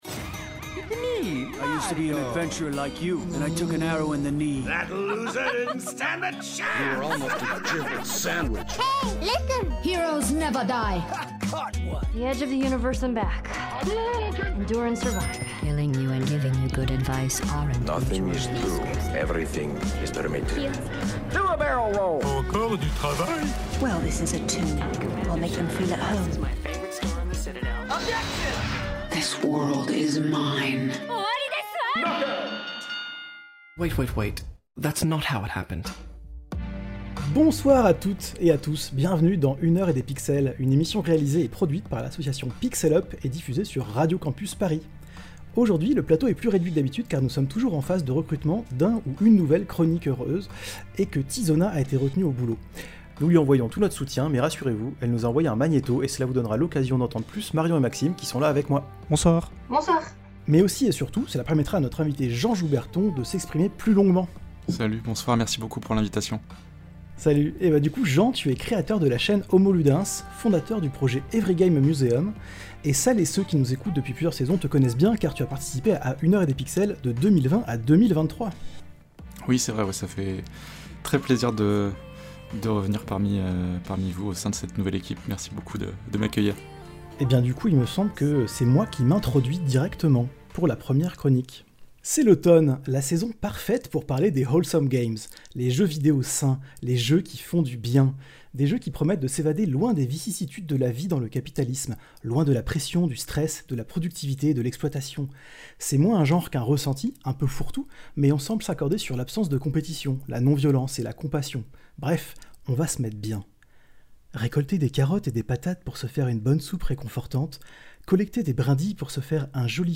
Émission diffusée le 1er novembre 2025 sur Radio Campus Paris.